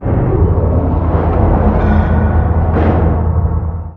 volcanotransferbegin.wav